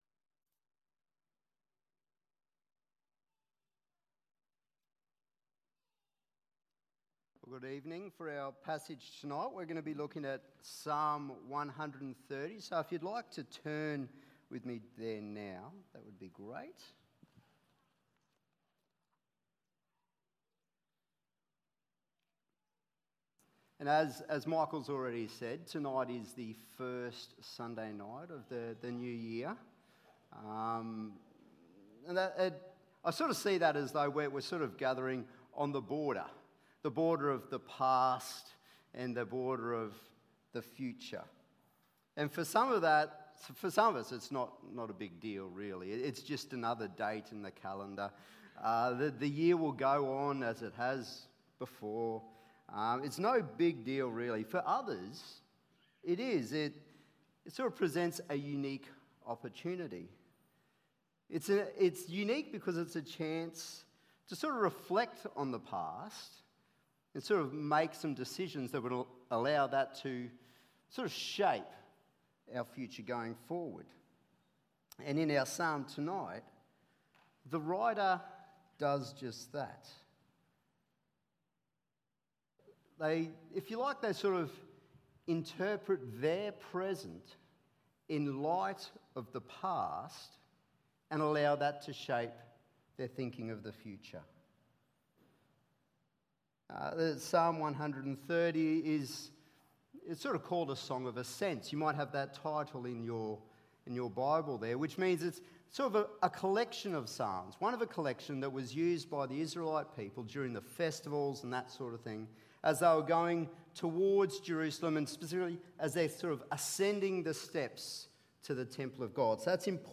Individual Message